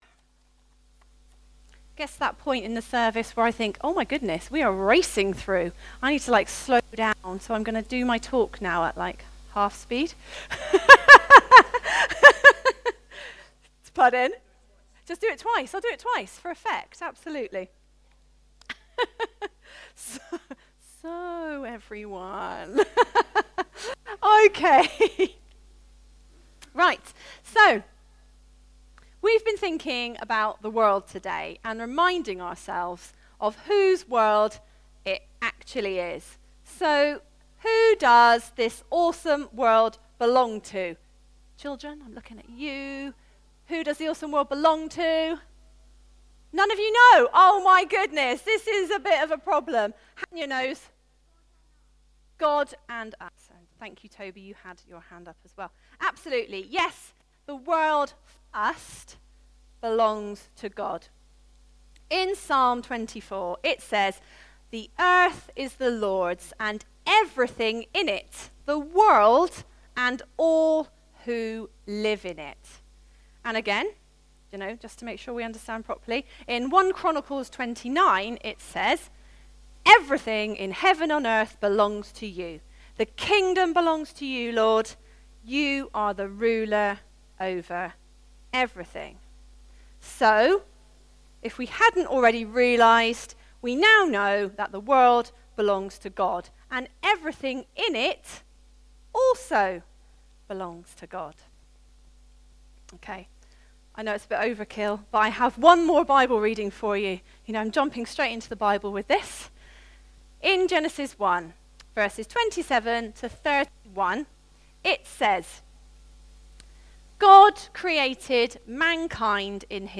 A message from the series "Stand Alone Sermons 2024."